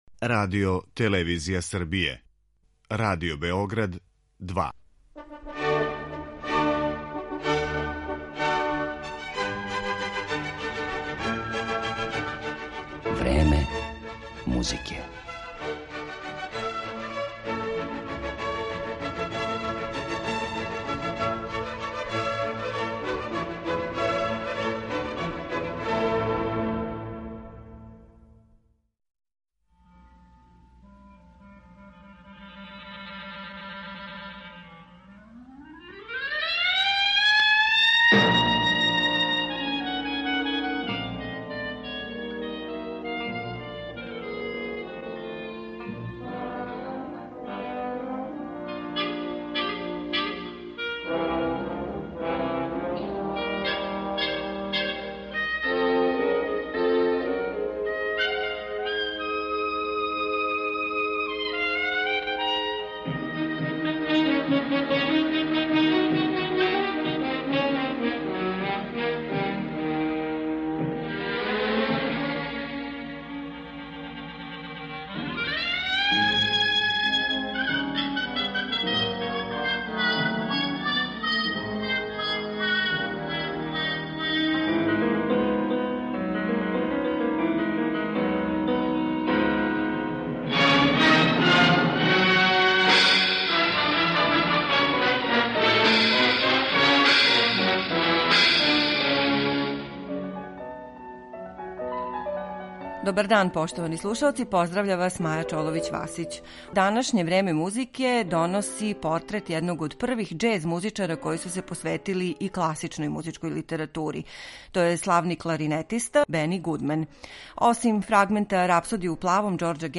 Бени Гудмен - 'краљ свинга' изводи класичну музику.